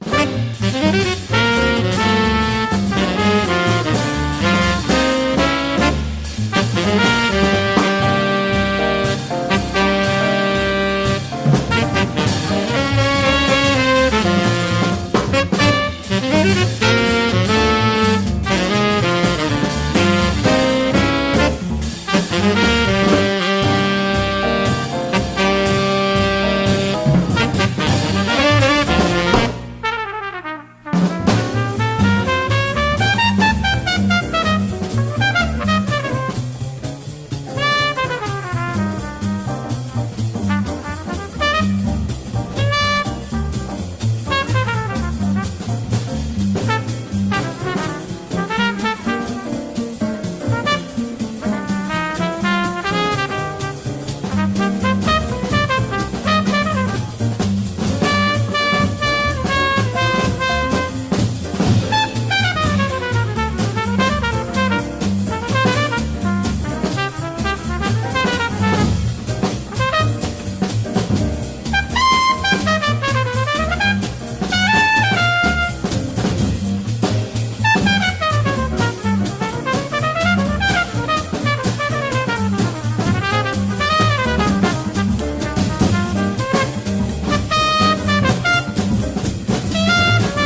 ROCK / 80'S/NEW WAVE. / NEW WAVE / ELECTRO
ガールズ・ラップも飛び出すN.Y.ファンキー・エレクトロ！